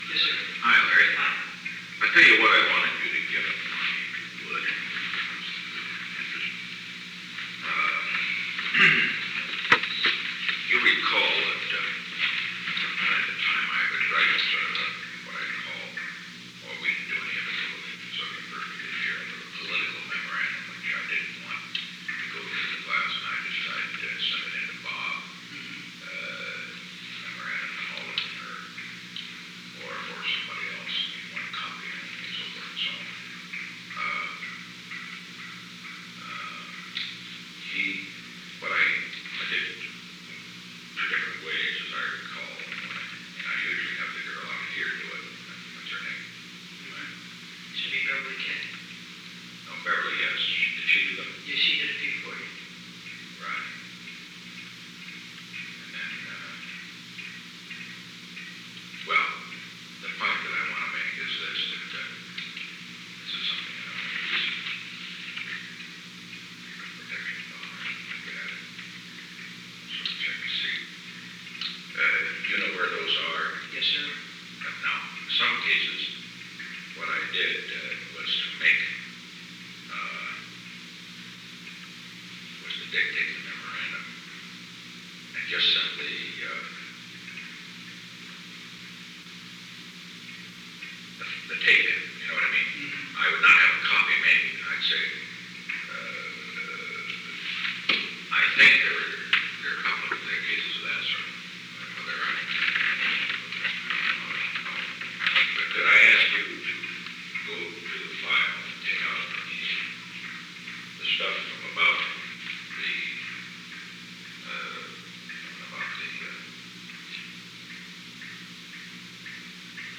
Secret White House Tapes
Conversation No. 917-5
Location: Oval Office